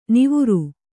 ♪ nivuru